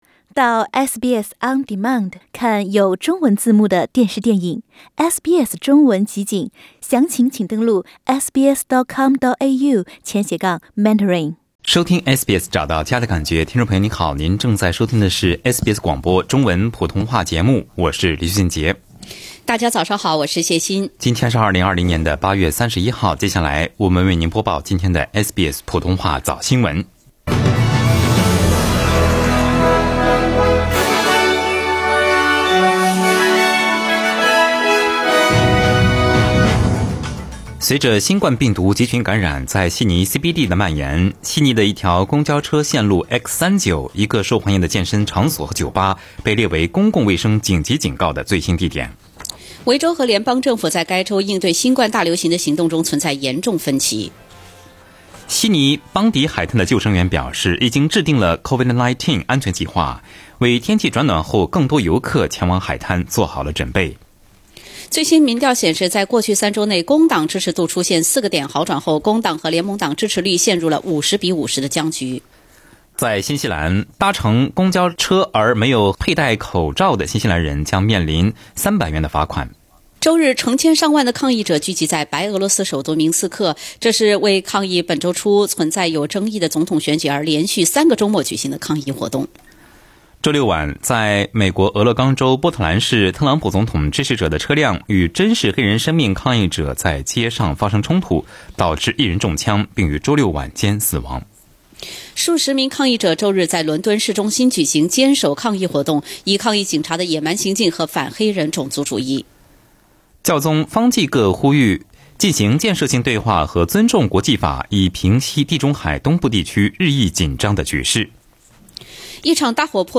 SBS早新闻（8月31日）